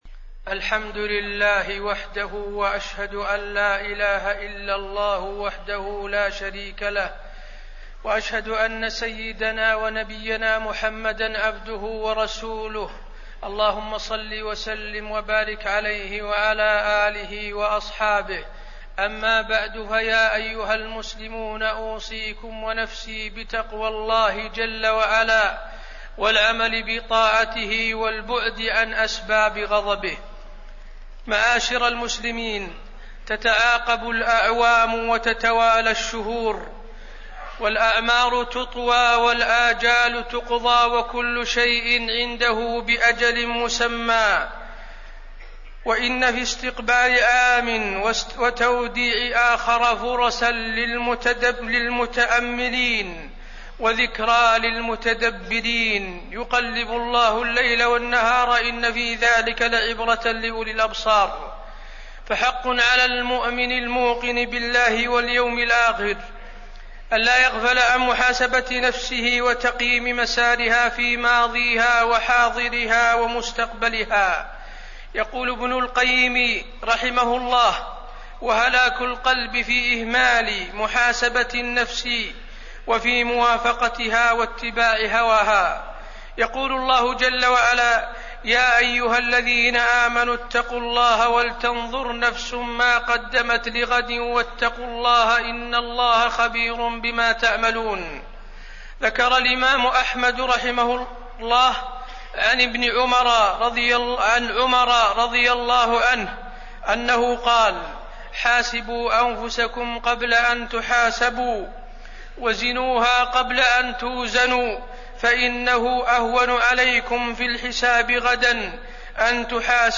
تاريخ النشر ٢٧ ذو الحجة ١٤٣١ هـ المكان: المسجد النبوي الشيخ: فضيلة الشيخ د. حسين بن عبدالعزيز آل الشيخ فضيلة الشيخ د. حسين بن عبدالعزيز آل الشيخ حاسبوا أنفسكم قبل أن تحاسبوا